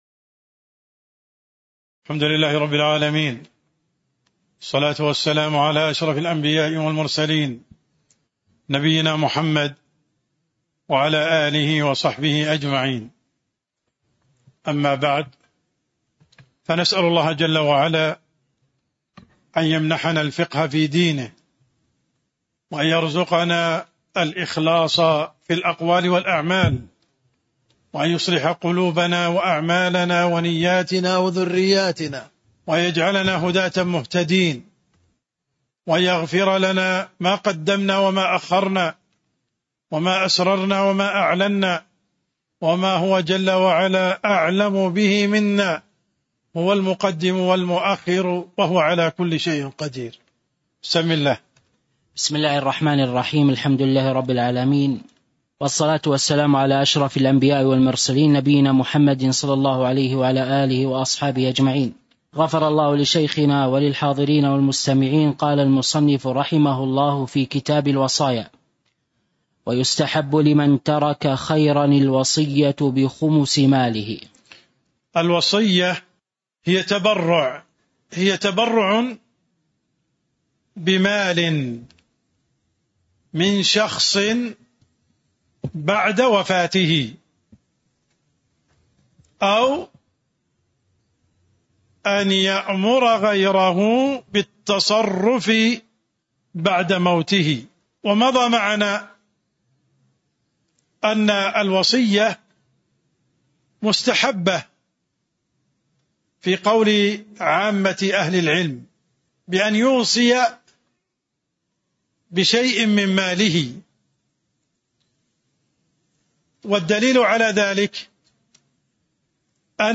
تاريخ النشر ١٩ ربيع الأول ١٤٤٤ هـ المكان: المسجد النبوي الشيخ: عبدالرحمن السند عبدالرحمن السند قوله: ويستحب لمن ترك خيراً الوصية بخمس ماله (02)